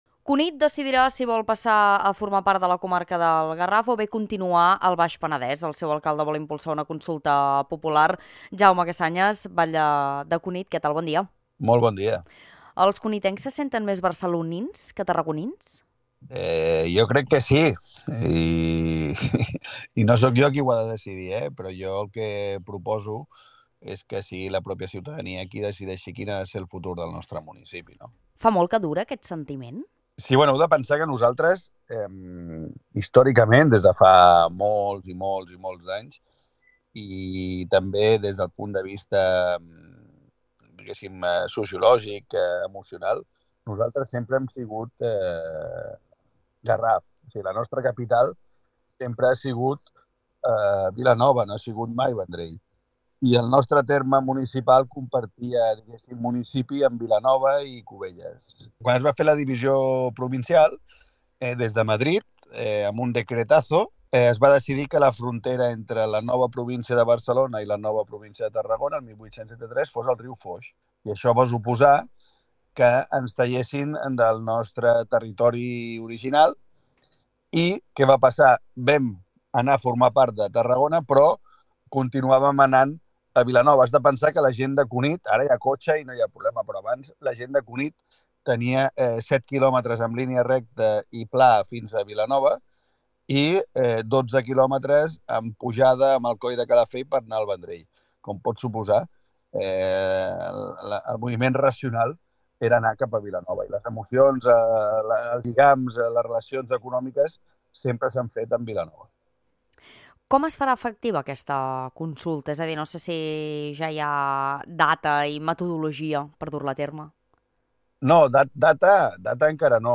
Entrevista - Jaume Casañas, alcalde de Cunit